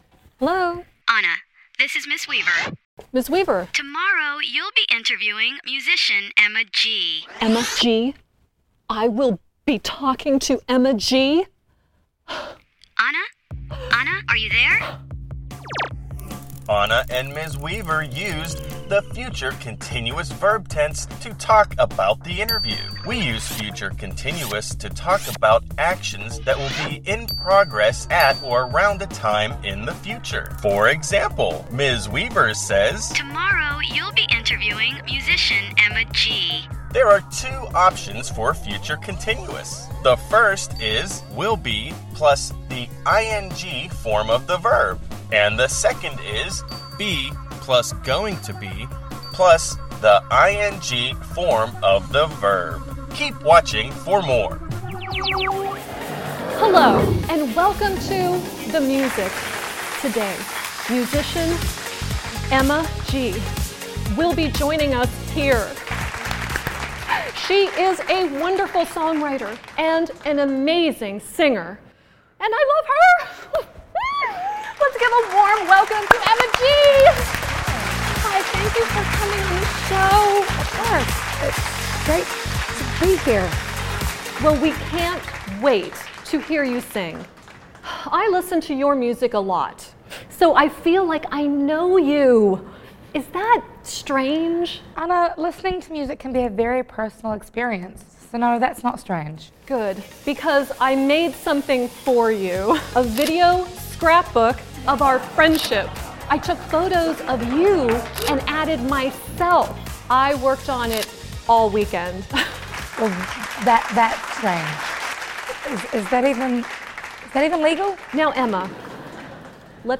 Hội thoại (Conversation)